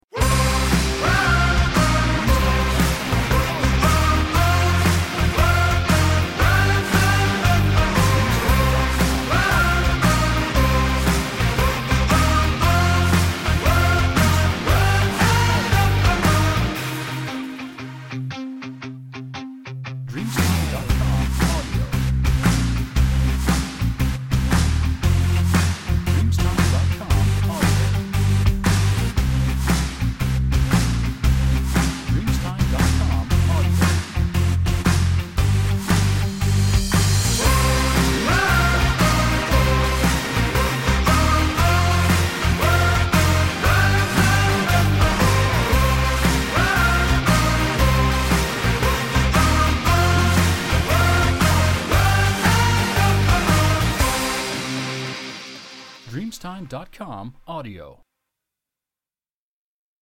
Energetic Indie Rock [60s]